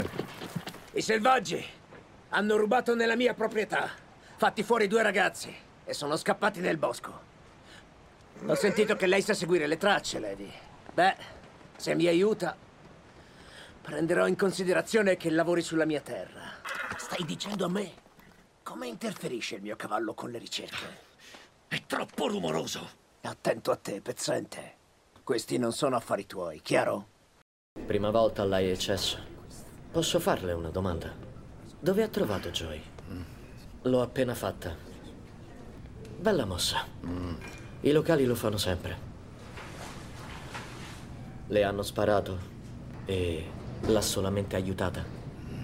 nel telefilm "Outer Range", in cui doppia John Ales e Tatanka Means.